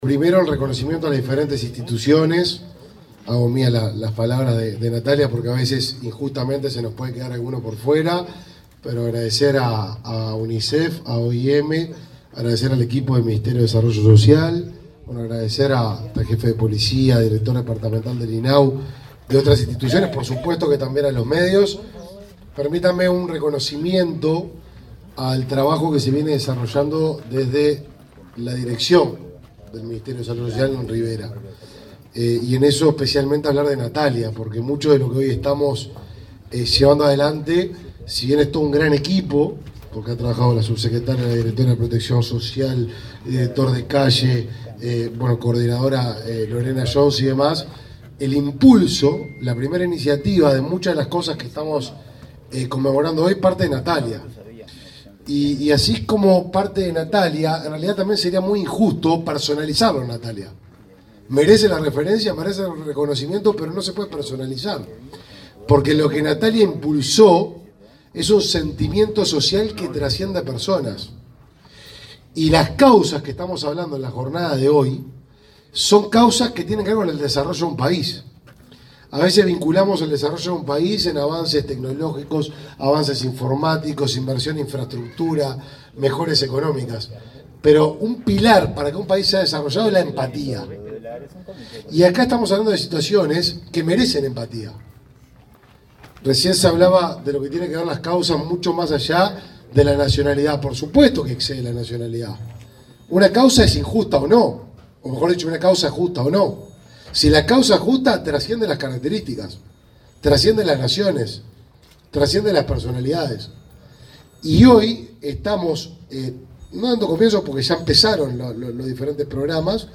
Palabras del ministro de Desarrollo Social, Martín Lema
Este jueves 19 en Rivera, el ministro de Desarrollo Social, Martín Lema, participó en el lanzamiento del programa Calle para ese departamento.